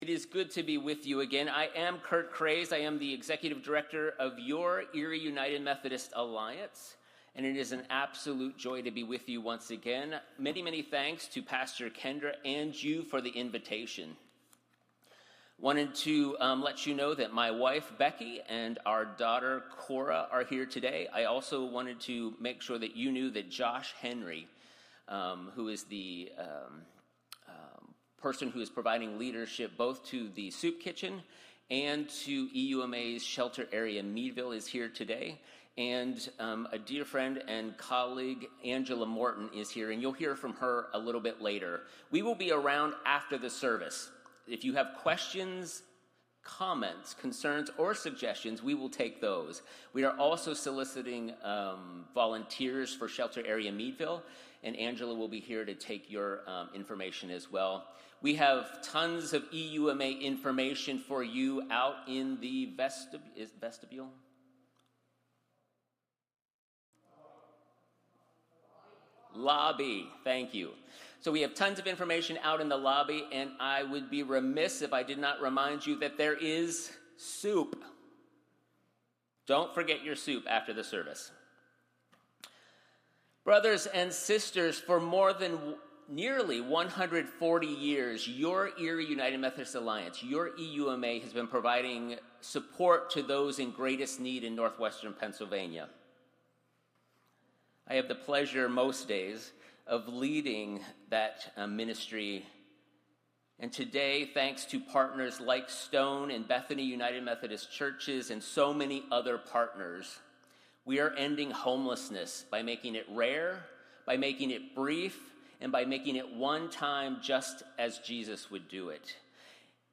Sermons | Stone UMC